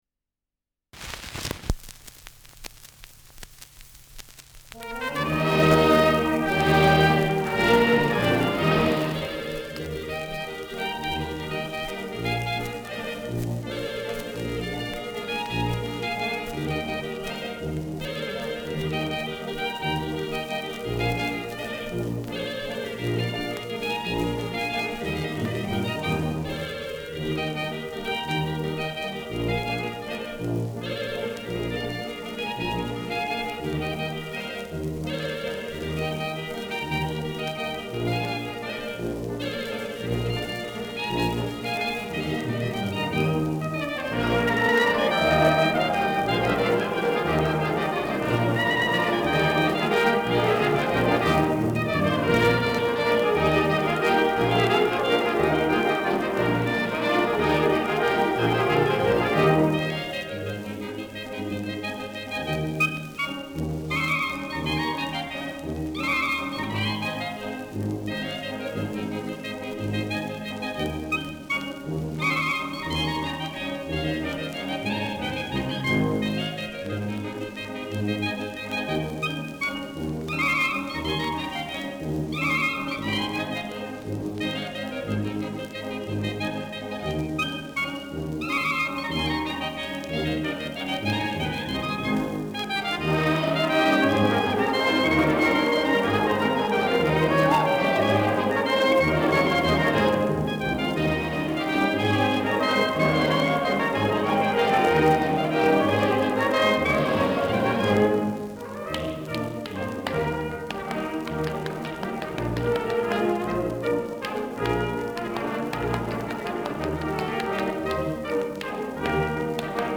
Schellackplatte
Große Besetzung mit viel Hall, die einen „symphonischen Klang“ erzeugt.
[Berlin] (Aufnahmeort)